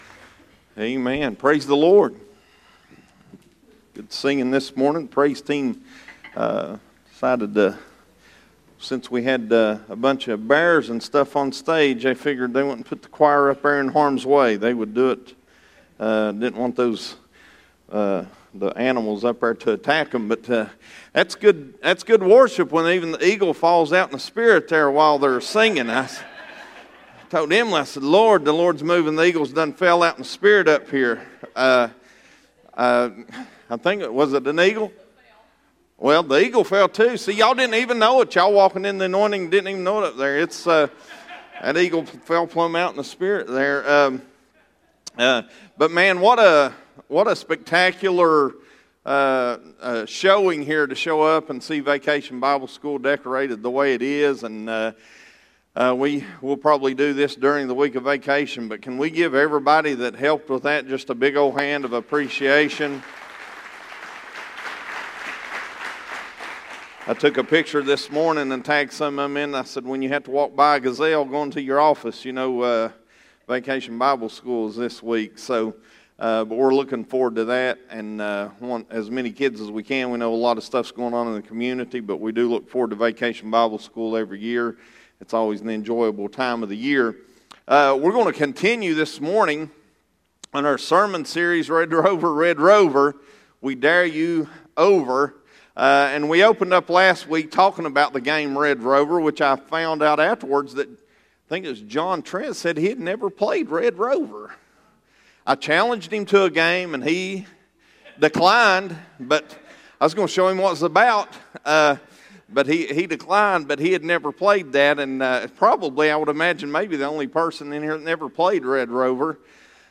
Red Rover...Red Rover Sermon Series